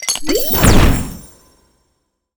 potions_mixing_alchemy_03.wav